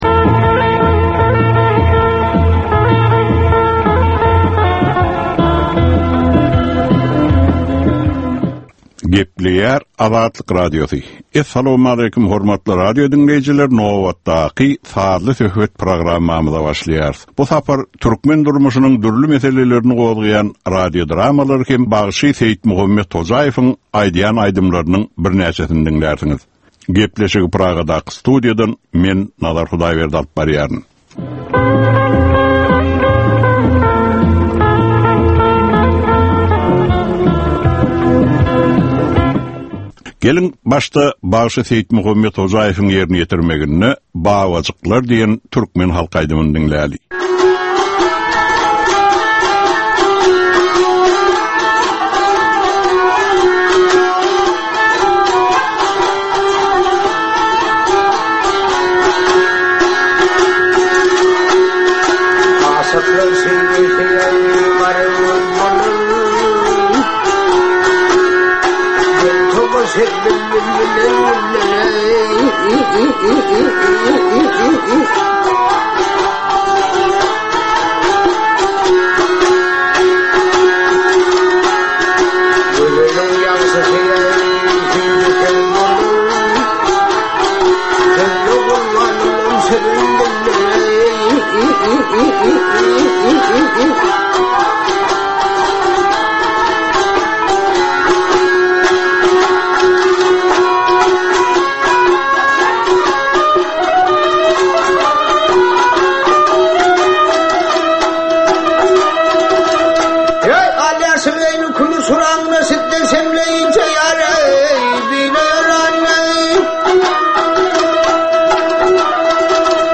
Türkmeniň käbir aktual meseleleri barada sazly-informasion programma.